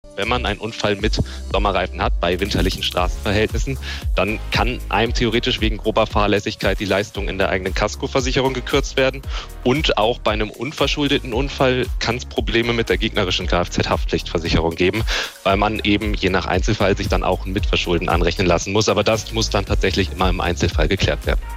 SWR3-Interview. Und auch bei einem unverschuldeten Unfall kann es Probleme mit der gegnerischen Versicherung geben: